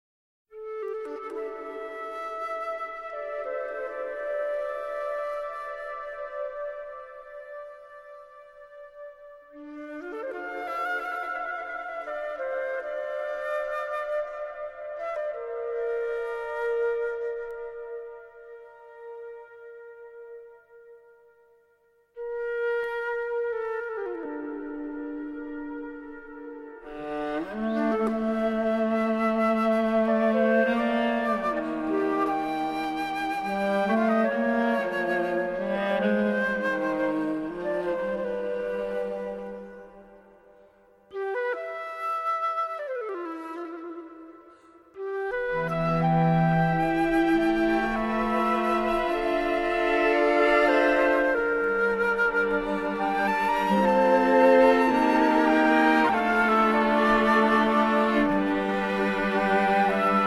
Атмосфера альбома поражает изысканностью.